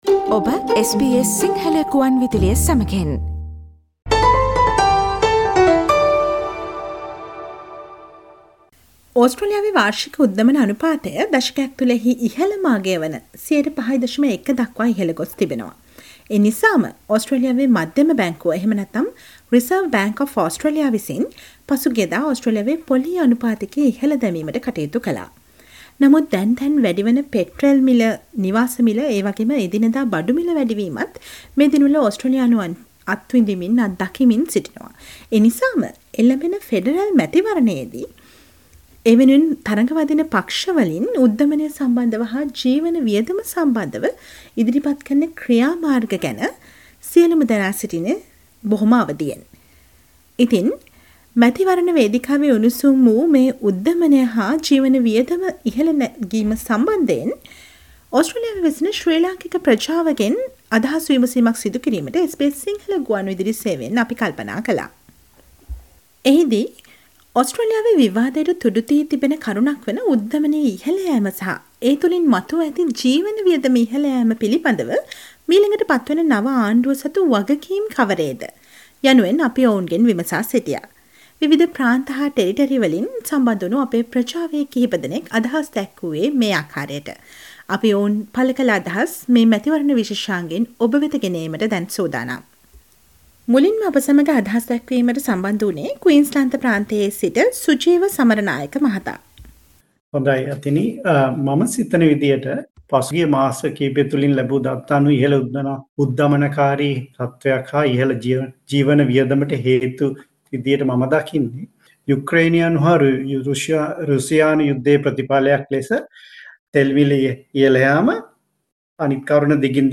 ඔස්ට්‍රේලියාවේ විවාදයට තුඩු දී ඇති කරුණක් වන උද්ධමනය ඉහල යෑම සහ ඒ තුලින් මතුව ඇති ජීවන වියදම ඉහල යෑම පිළිබඳව මීළඟට පත්වන නව ආණ්ඩුව සතු වගකීම් කවරේද යන්න ඔස්ට්‍රේලියාවේ වෙසෙන ශ්‍රී ලංකේය ප්‍රජාවේ කිහිපදෙනෙකු දැක්වූ අදහස් වලට සවන් දෙන්න.